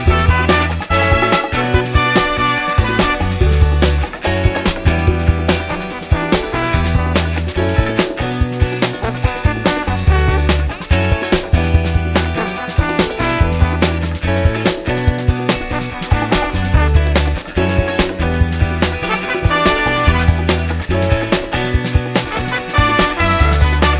no Backing Vocals Soul